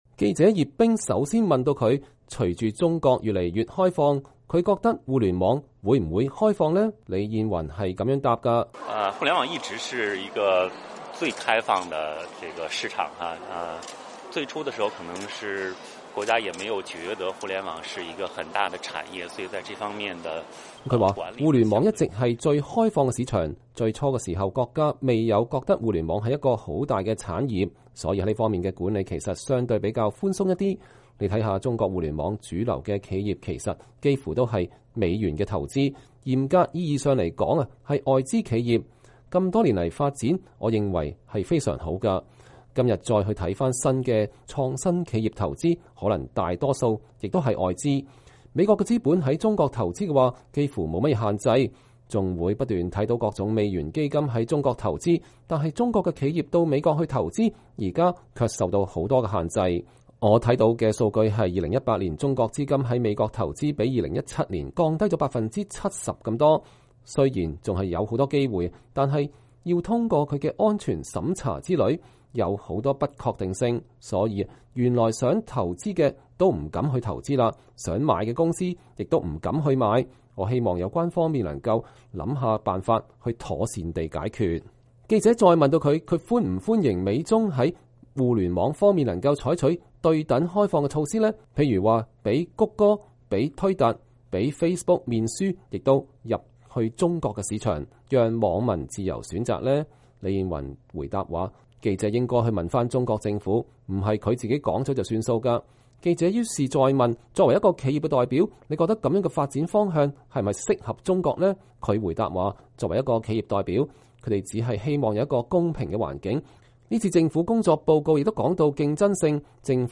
百度創辦人、全國政協委員李彥宏2019年3月11日在政協會議上對記者講話。